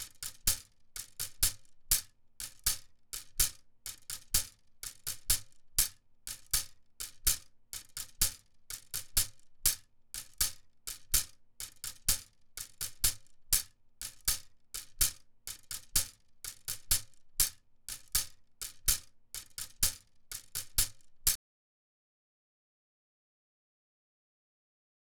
The Pū’ili is a split bamboo rattle and dancers often sat facing each other creating rhythms by hitting their bodies and the ground. The rustling instrument can sound like rainfall when tapped.
Pū’ili
Sticks.wav